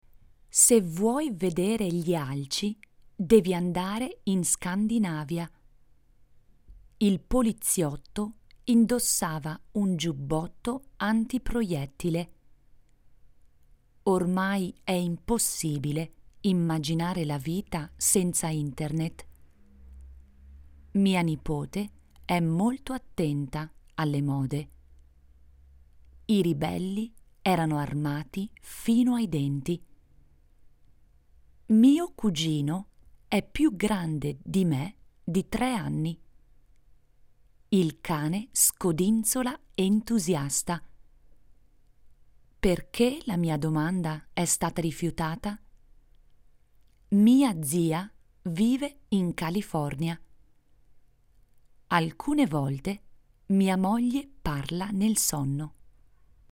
Voce fresca, elegante, chiara, sofisticata, sexy, civettuola
Sprechprobe: eLearning (Muttersprache):